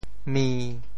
咪 部首拼音 部首 口 总笔划 9 部外笔划 6 普通话 mī 潮州发音 潮州 mi1 文 中文解释 咪 <象> 形容猫叫声或唤猫声 [meow,waw]――常叠用。